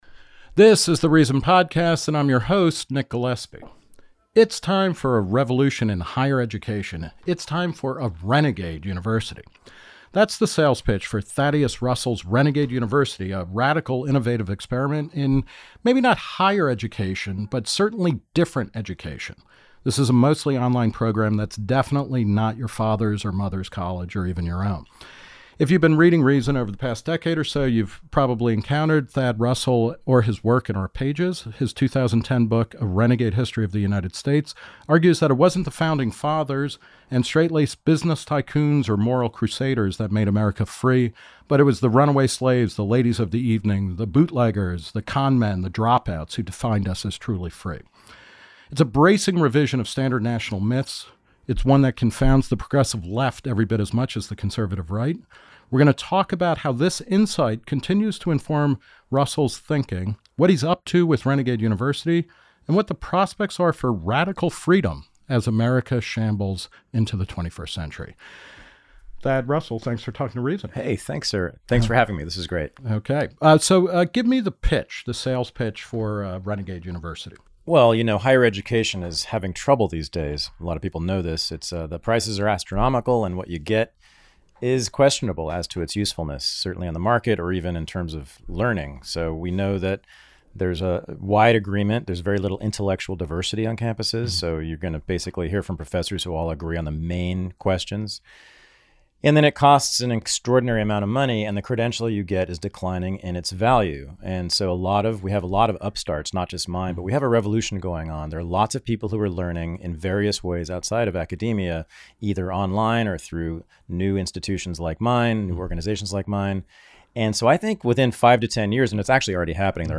The Reason Interview With Nick Gillespie